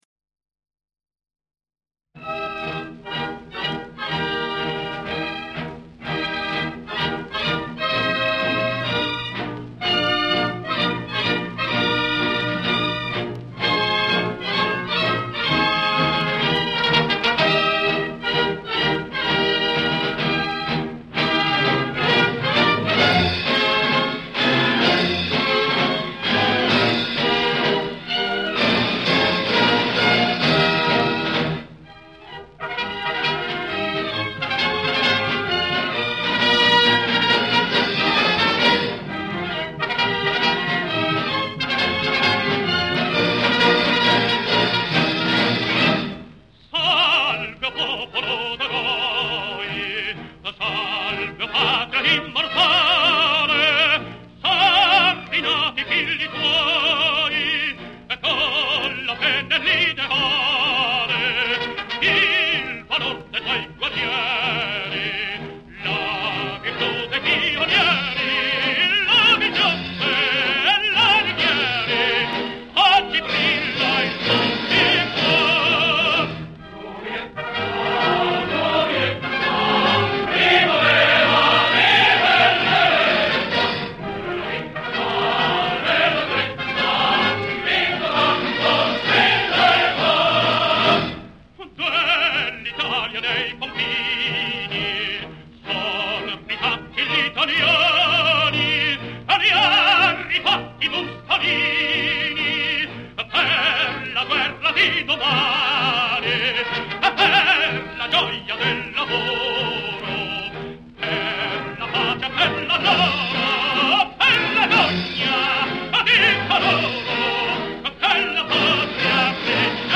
>Benjamino Gigli
Giovinezza_Gigli.mp3